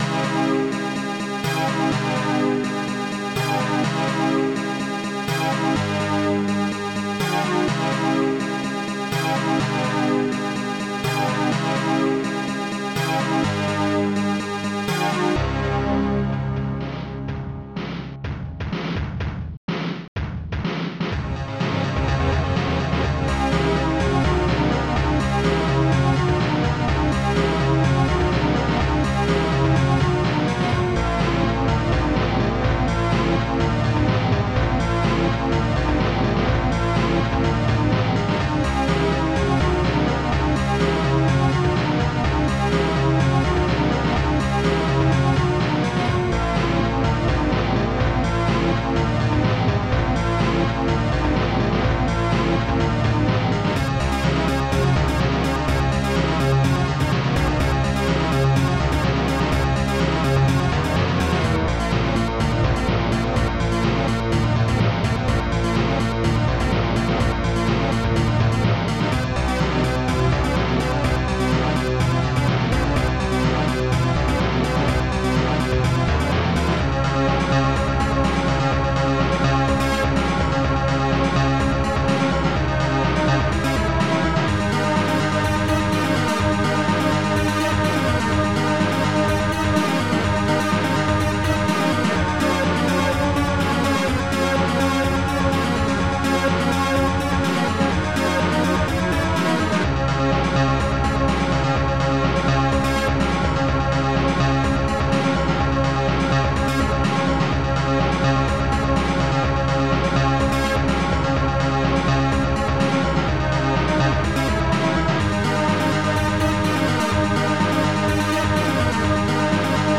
Protracker Module
ST-18:stormstrings2 ST-16:d50-livingcall ST-15:fredbass ST-15:fredsnare ST-16:d-50-hapsi